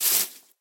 grass2.ogg